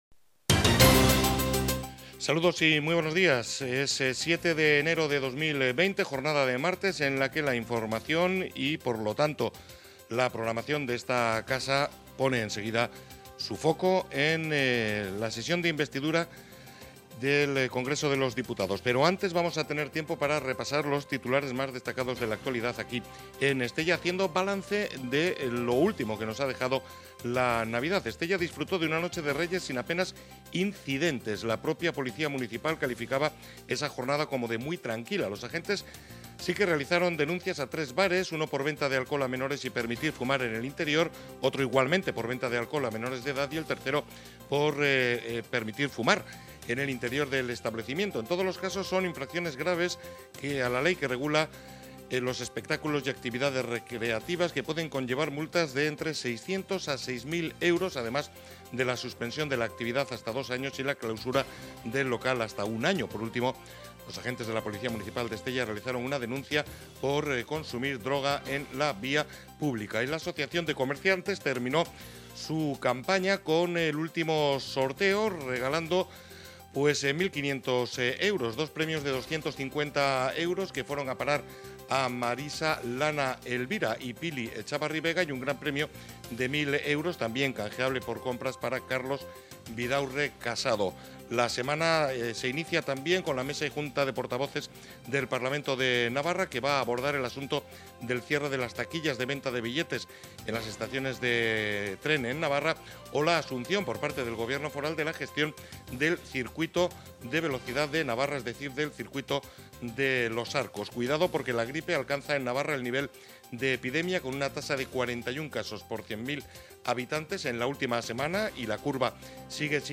Los titulares de Onda Cero Estella
7-de-enero-titulares-onda-cero-estella.mp3